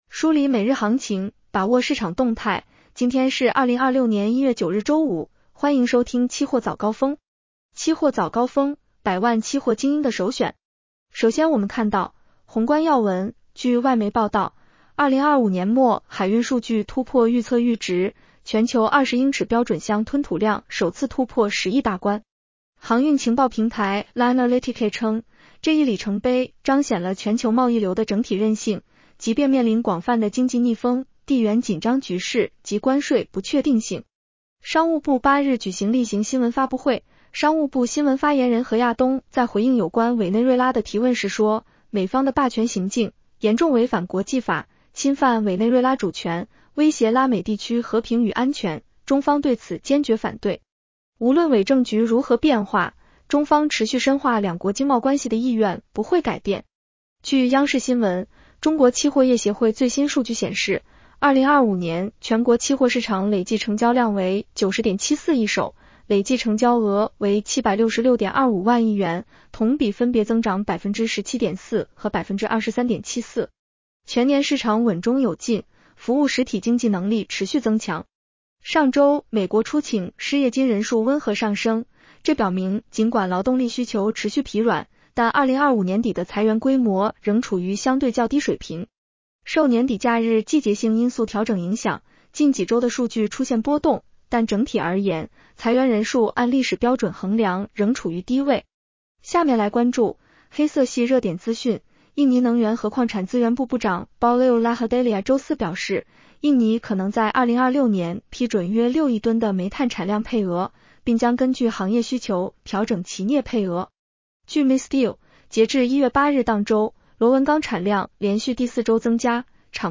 期货早高峰-音频版 女声普通话版 下载mp3 热点导读 1.2025年全国期货市场累计成交额同比增长23.74%。